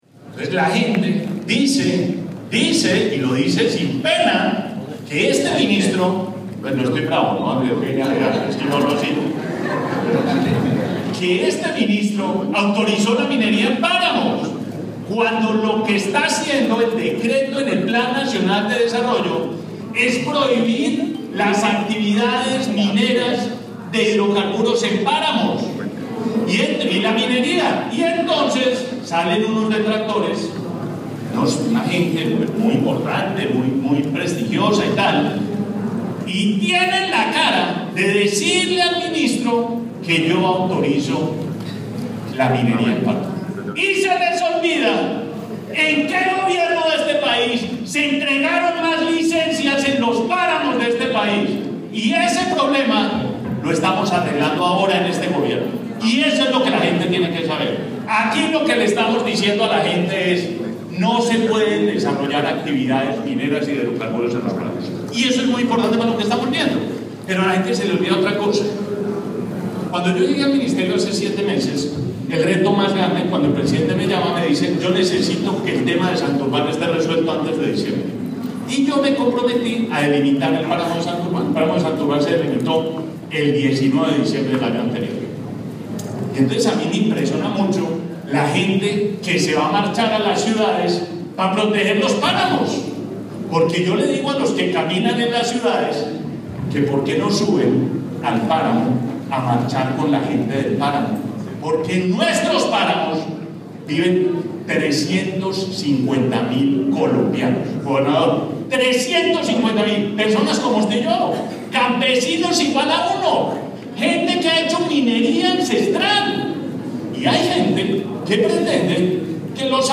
El Ministro de Ambiente y Desarrollo Sostenible, Gabriel Vallejo, participo hoy en el VII Foro Orinoquense sobre Cambio Climático que se llevó a cabo en la capital del Meta donde respondió inquietudes de la comunidad sobre las políticas ambientales del país.
Declaraciones del Ministro de Ambiente y Desarrollo Sostenible, Gabriel Vallejo López